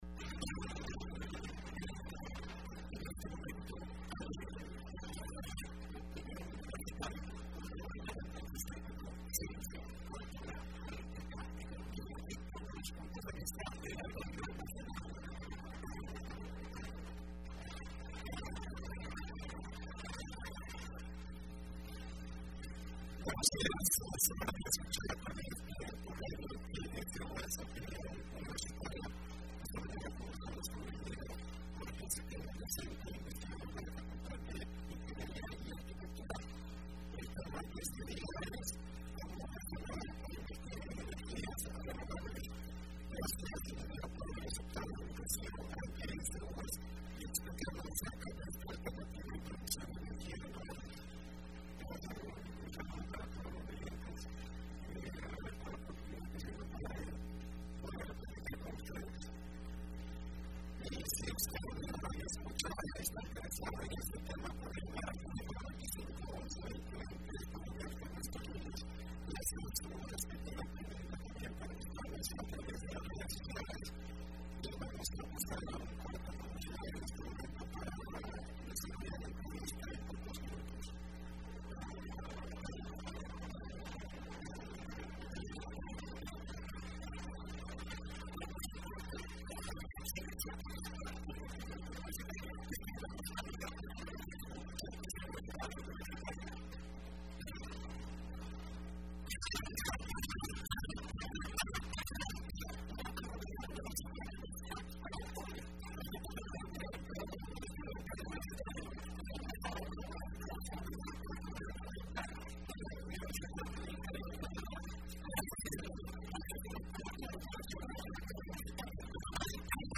Entrevista Opinión Universitaria (14 de Julio 2015): Cómo El Salvador puede invertir en energías renovables.